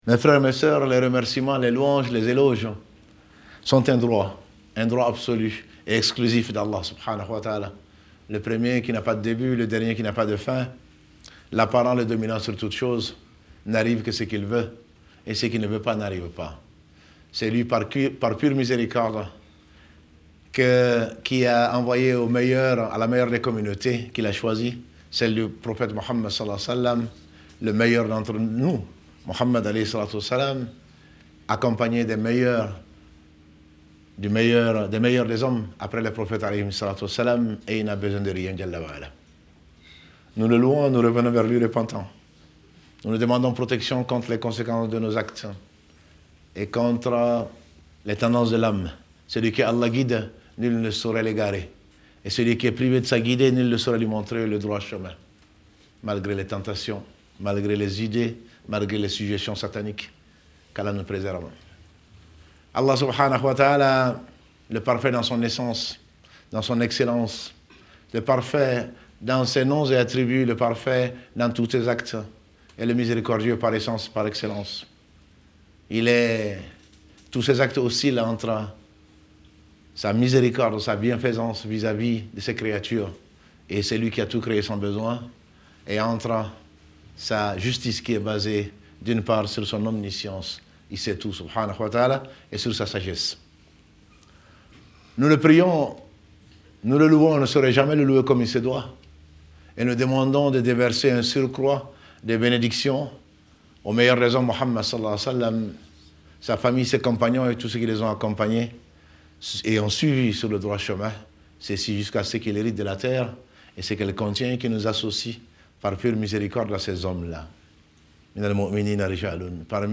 Djoumu'a du 30/08/2019